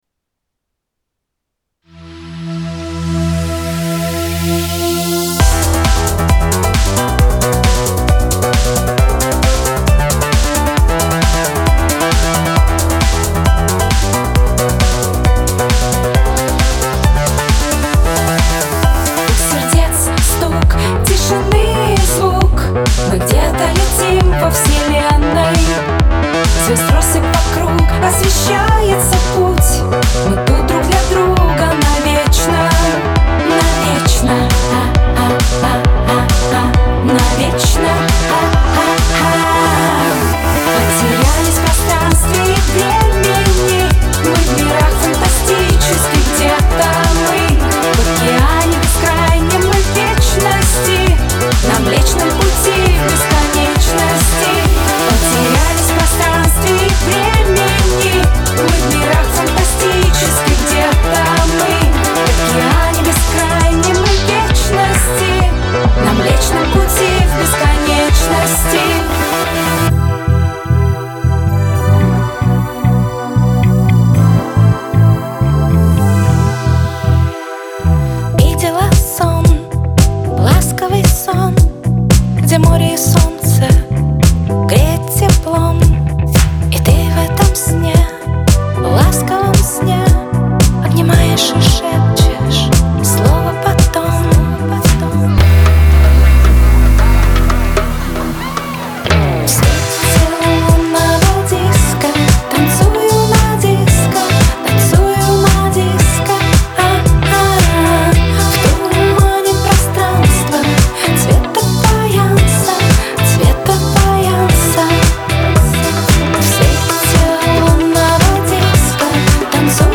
pop , дуэт
dance